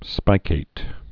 (spīkāt)